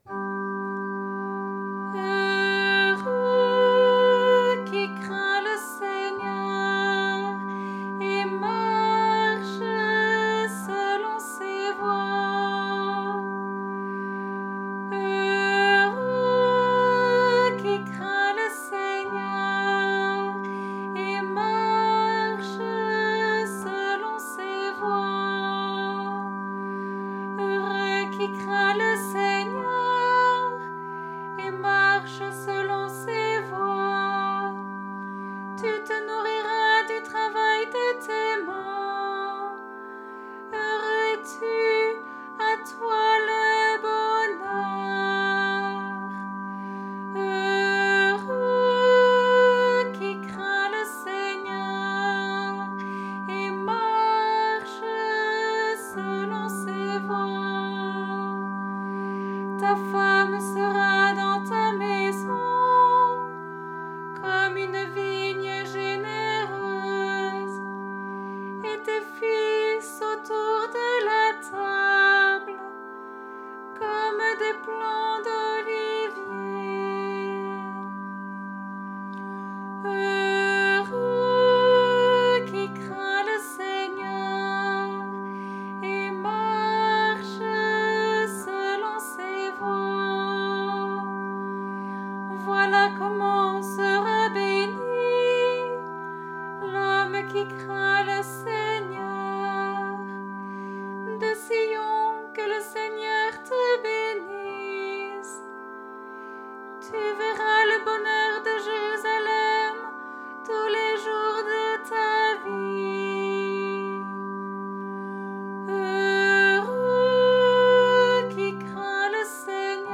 Le fichier audio vous aide à chanter !
Antienne pour le 27e dimanche du Temps Ordinaire (Année B)